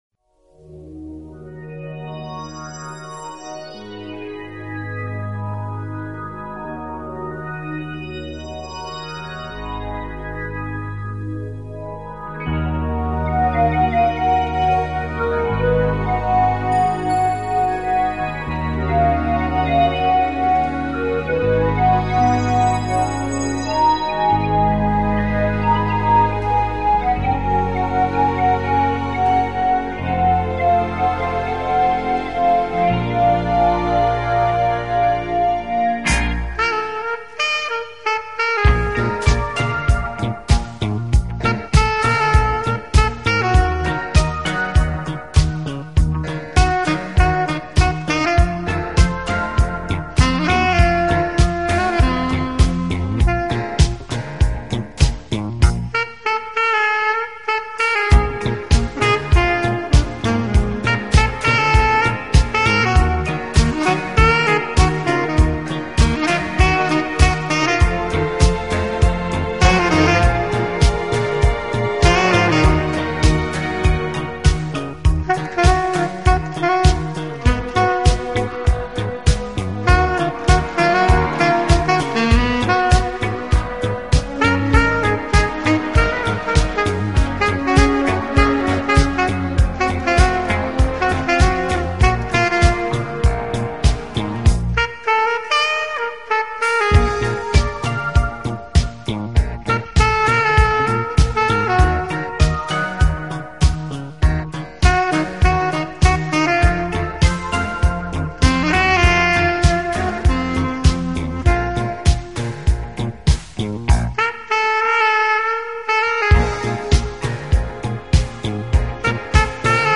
Genre : Instrumental